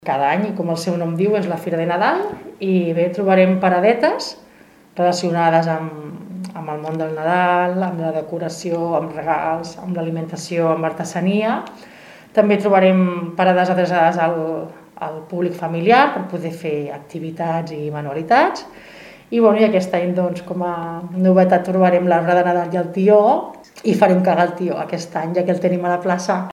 Els productes que es posaran a la venda i que podreu trobar a la fira seran d’artesania, productes de decoració nadalenca, tions, productes de regal i productes d’alimentació nadalenca envasada. Ho explica la regidora de promoció econòmica, Nàdia Cantero.